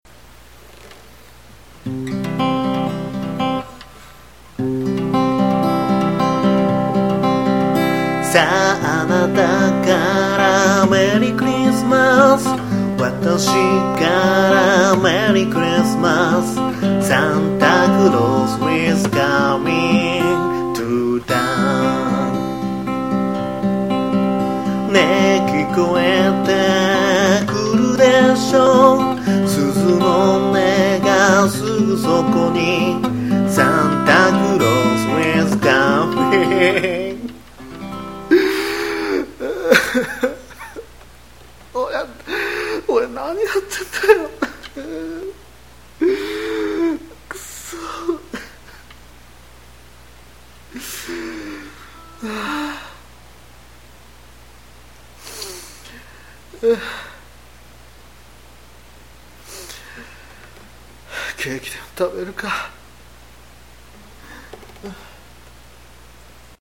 せっかくなのでおまけ即興一発録り！！
xmas.mp3